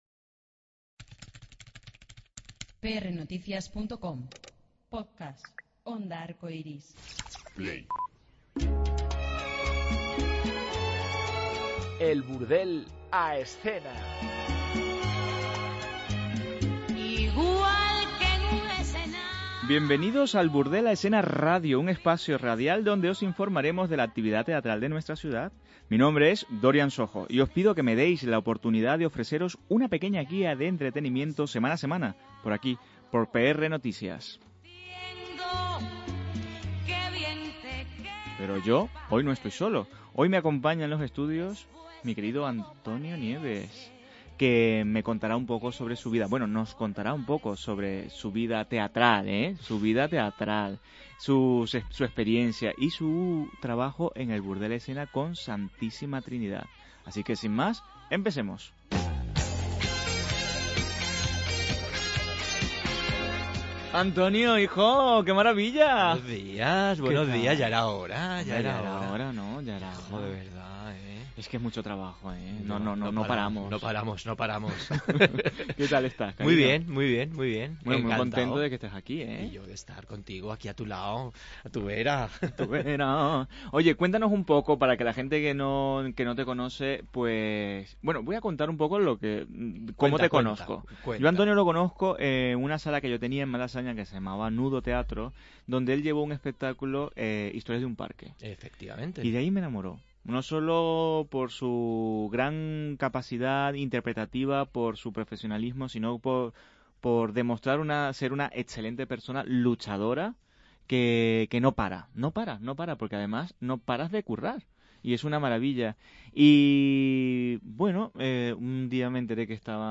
en una divertida entrevista